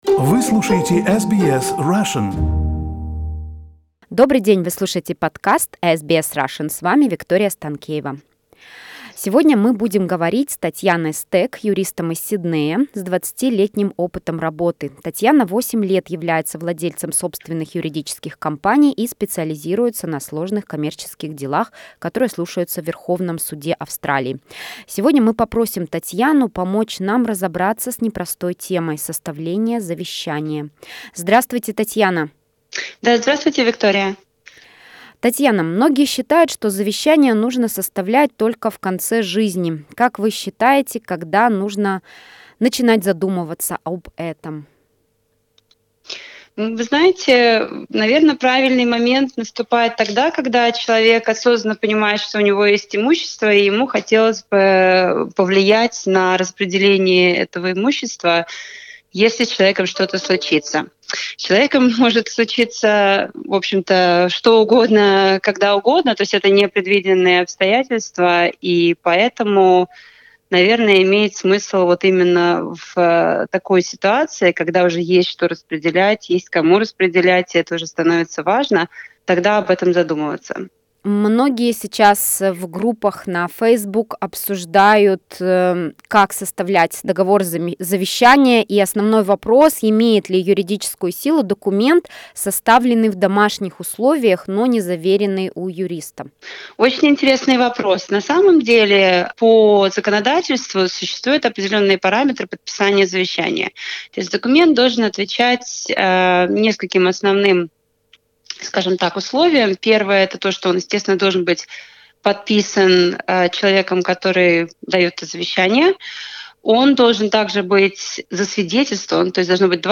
В интервью